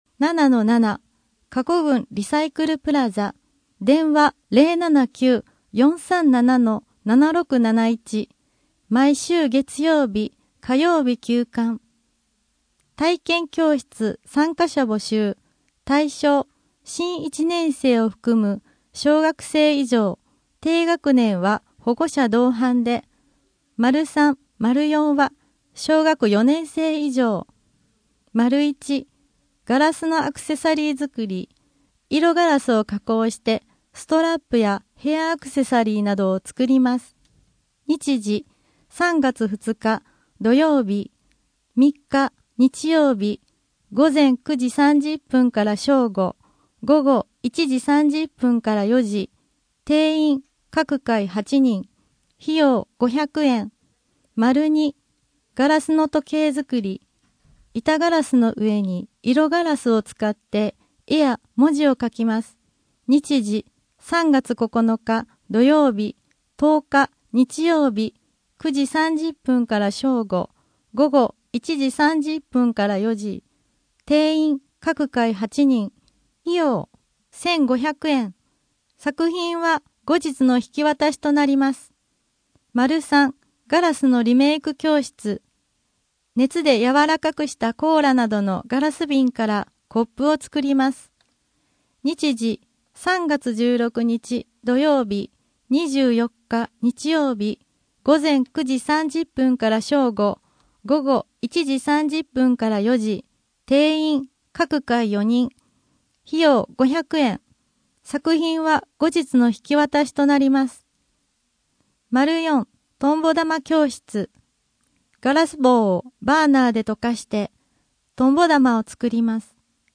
声の「広報はりま」3月号
声の「広報はりま」はボランティアグループ「のぎく」のご協力により作成されています。